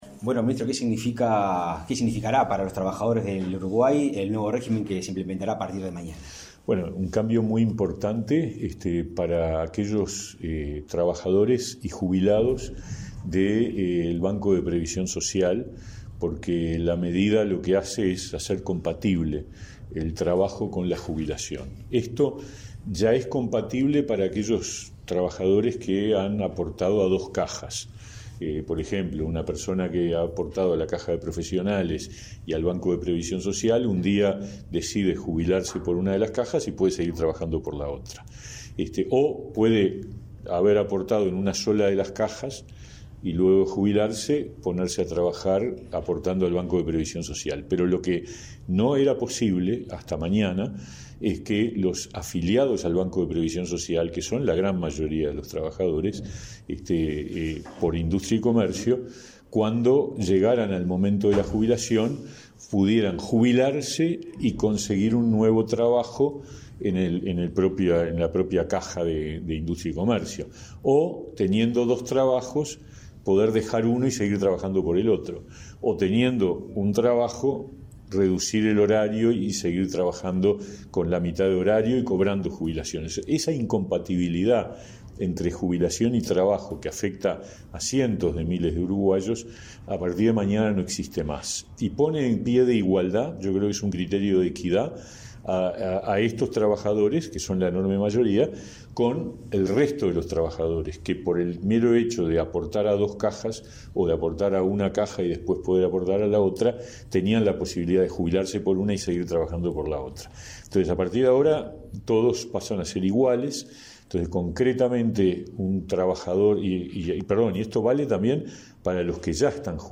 Entrevista al ministro de Trabajo y Seguridad Social, Pablo Mieres